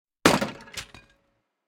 guard-crash.ogg